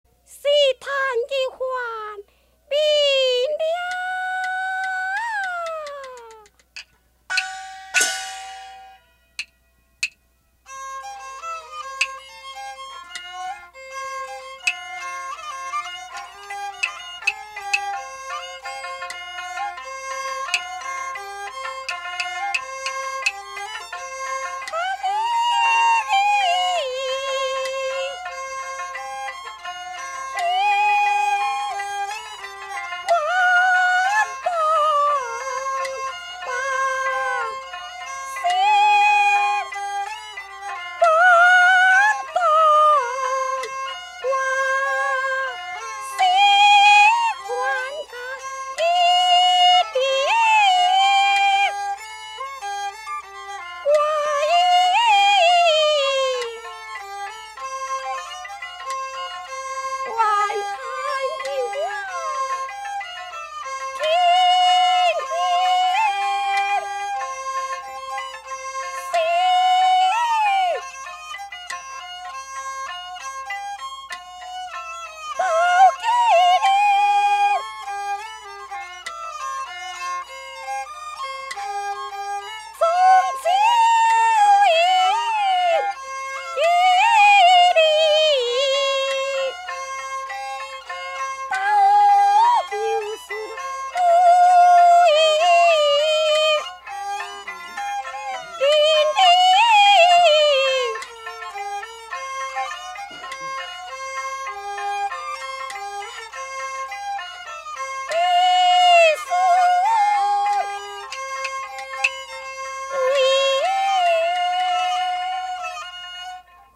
戲曲 - 打春桃選段 | 新北市客家文化典藏資料庫